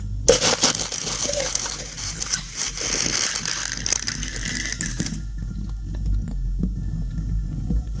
Звук поноса и кач на заднем плане
toilet12.wav